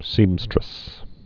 (sēmstrĭs)